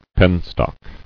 [pen·stock]